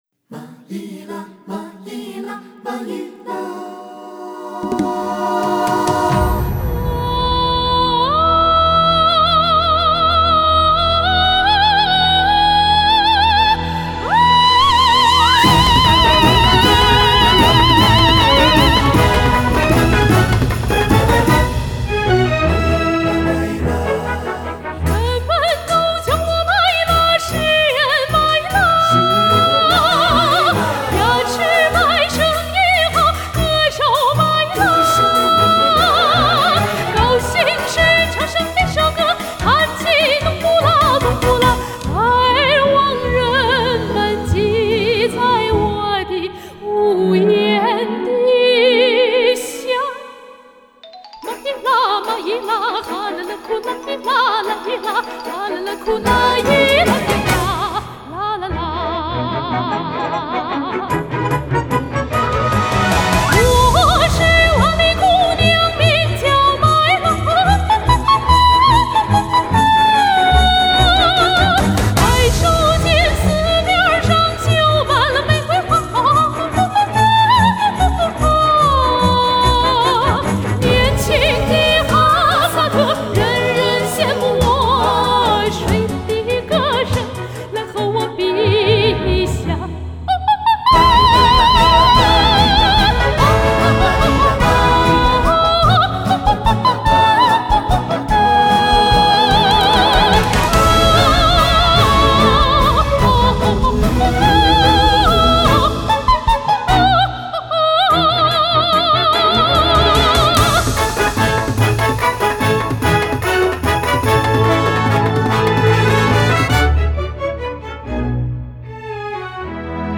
在歌曲中加入美声式的花腔技巧
哈萨克民歌